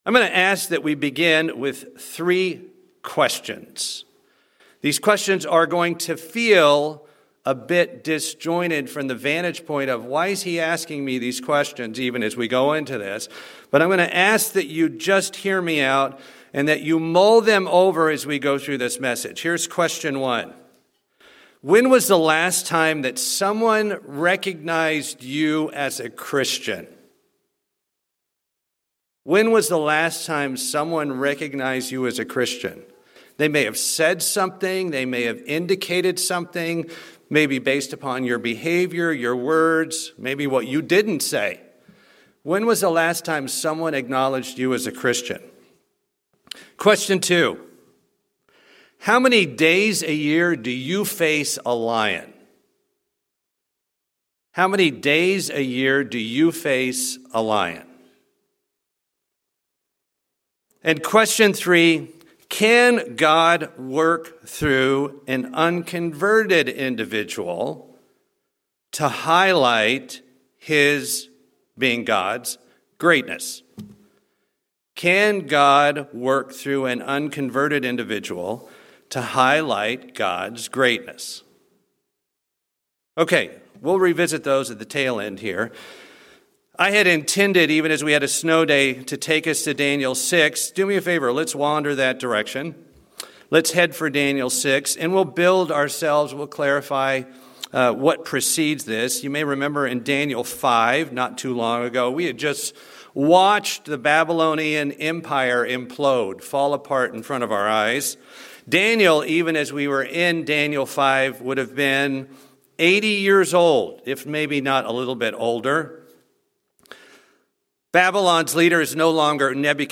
Sermons
Given in Buford, GA Atlanta, GA